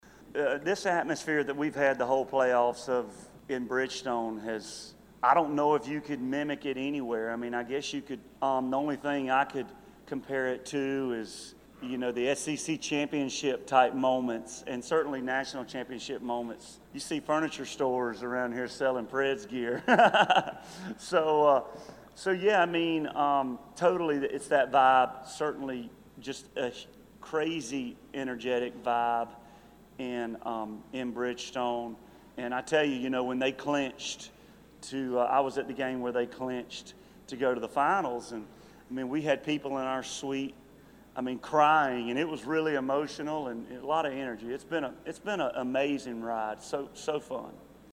Audio / Prior to putting on a pre-game show on the roof of Tootsies in downtown Nashville, Luke Bryan talks about the energy and excitement Music City is enjoying because of the Nashville Predators NHL team.